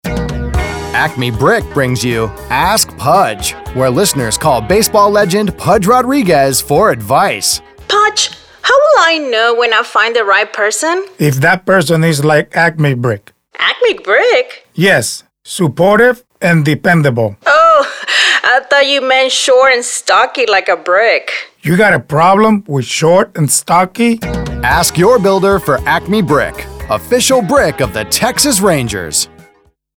Male – Funny, Celebrity, Sports